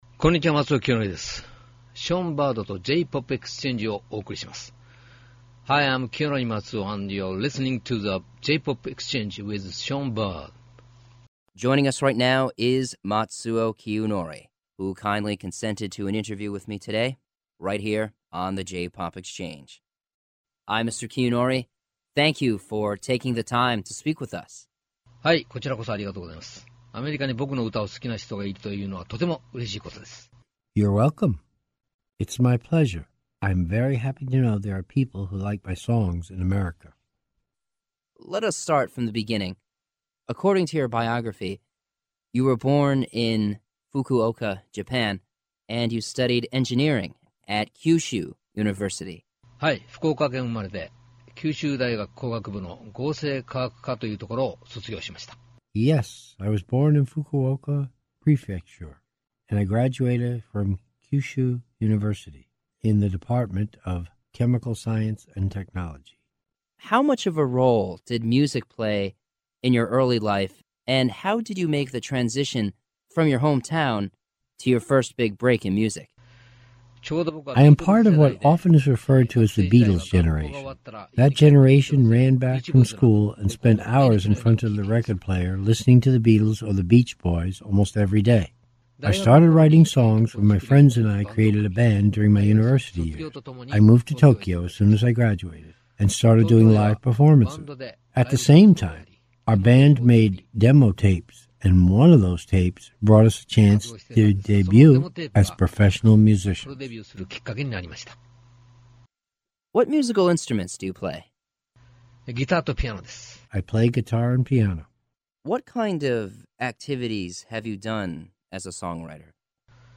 View Transcript of Radio Interview